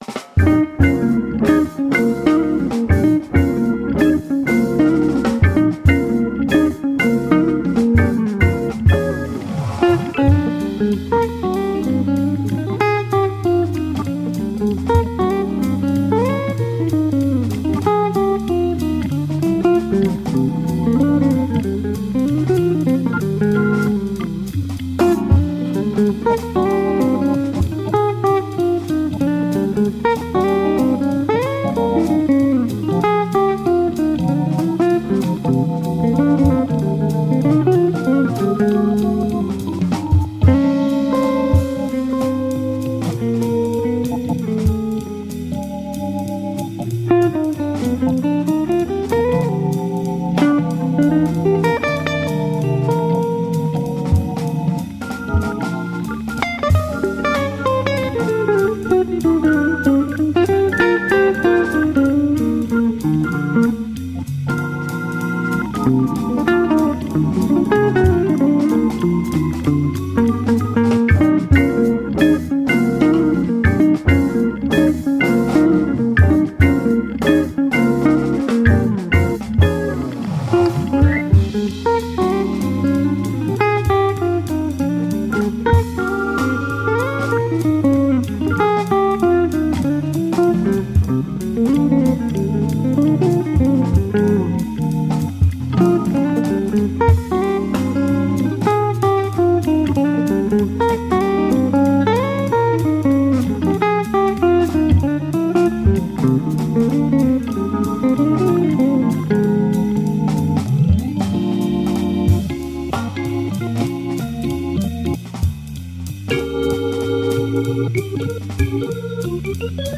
are typical blues ballads
Tagged as: Jazz, World, Background Mix, World Influenced